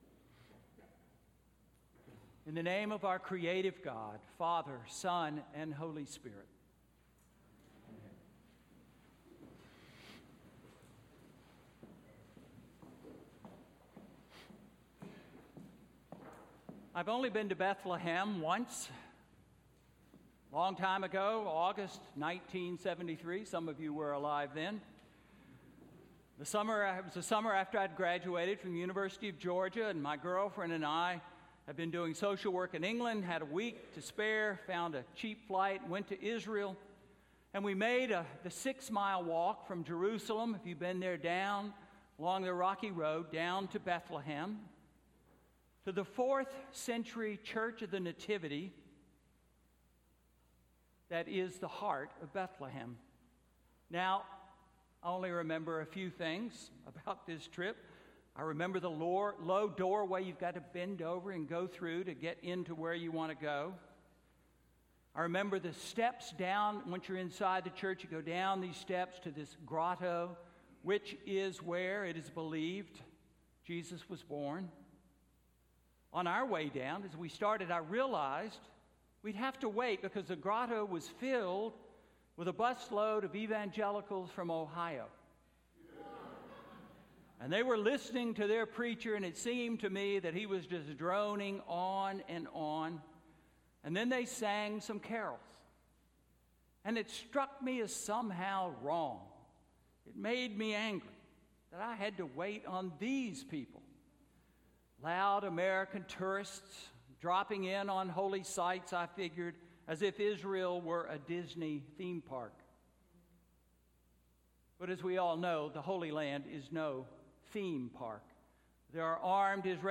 Sermon–Christmas Eve–2017